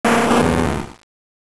サウンド素材「ポケモン鳴き声」